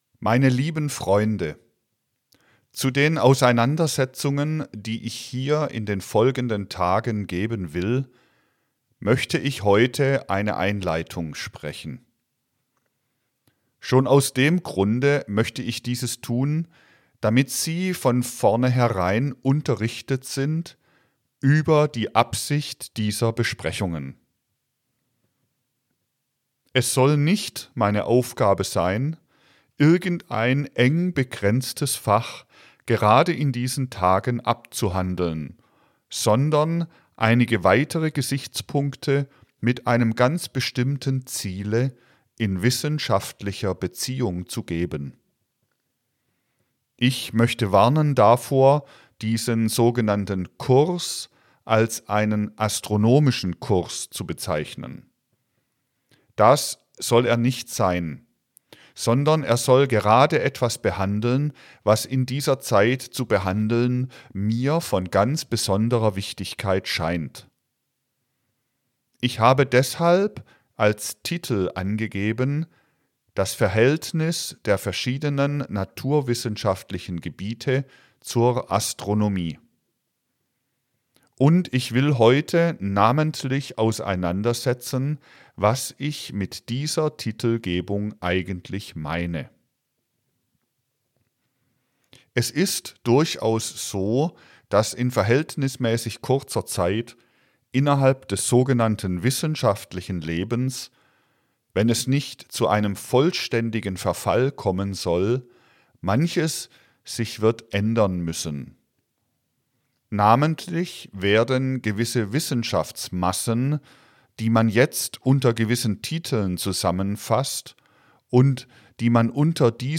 Audiobuch als Podcast ... Vortrag 01 Stuttgart, 01.